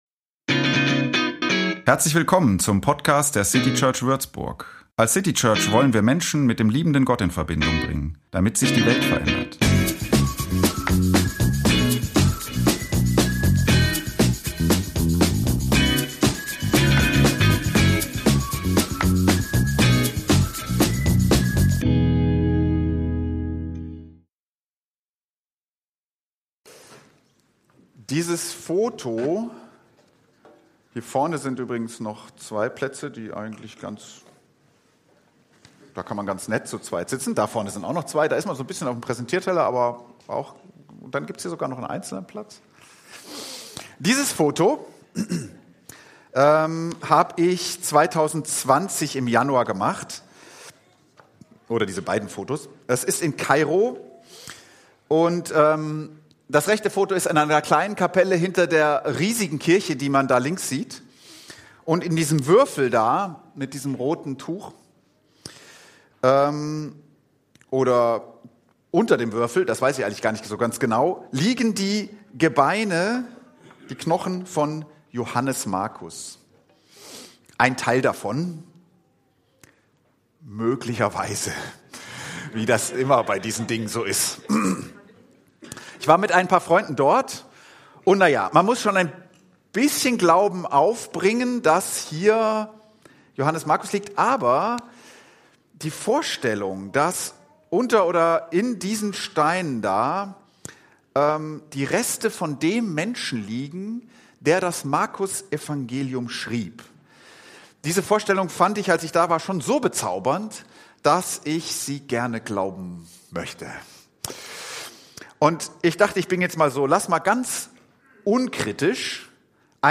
Die Sketchnotes zur Predigt: Anregungen und Fragen zur Pre…